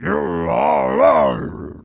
Voice clip of Bowser taunting in Mario Power Tennis